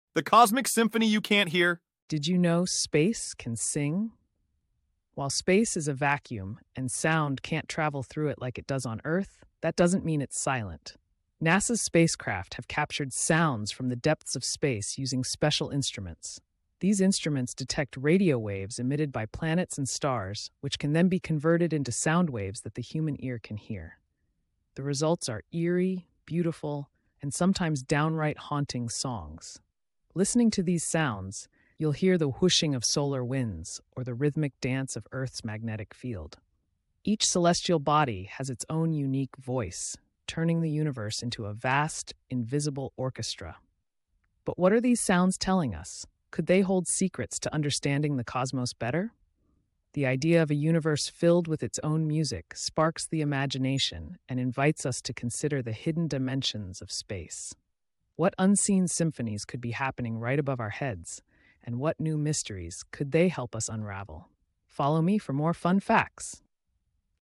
🌌🎶 Did you know space can 'sing'? Discover the cosmic orchestra captured by NASA, where planets and stars emit 'sounds' through radio waves, creating eerie yet beautiful melodies.